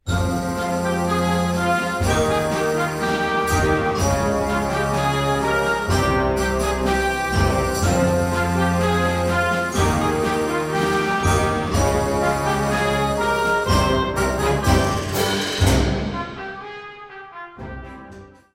Gattung: Jugendblasorchester
Besetzung: Blasorchester
A vigorous combination of the Paso Doble and the Flamenco